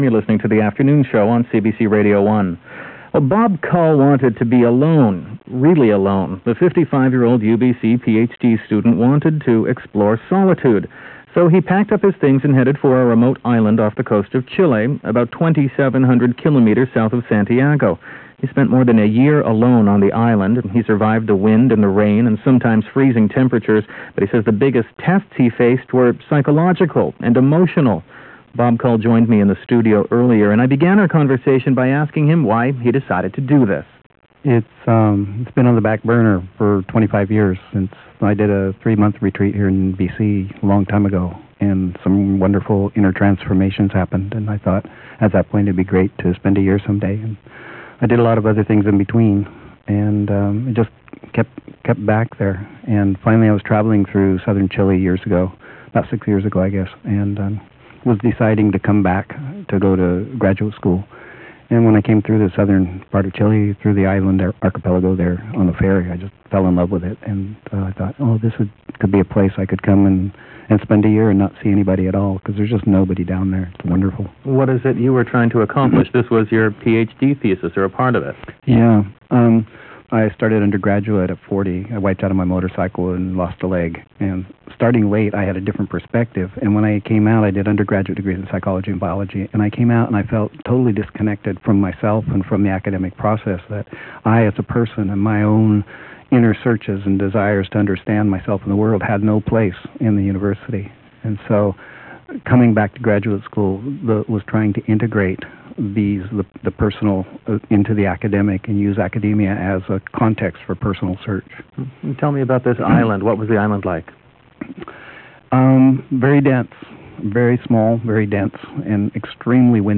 (14 minute interview)